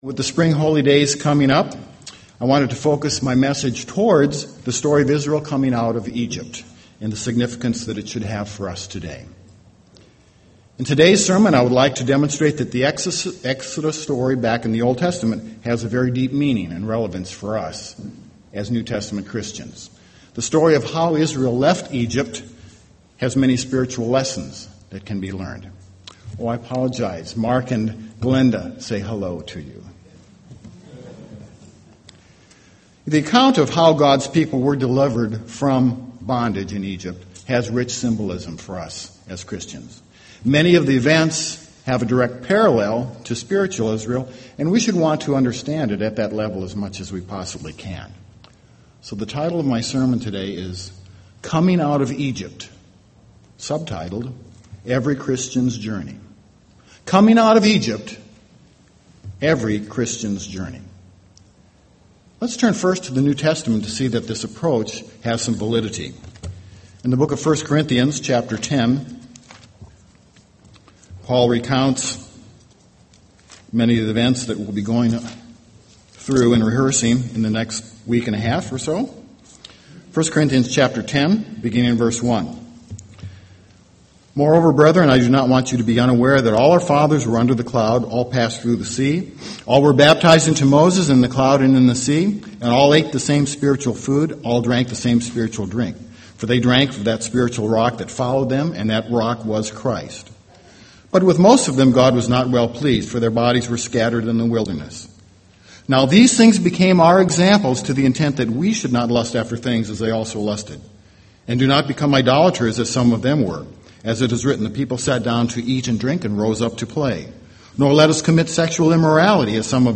This sermon reviews the major events of Israel being transformed from a group of slaves in Egypt into God's chosen nation. Parallels are drawn between those events and the steps or phases of the life of New Testament Christians.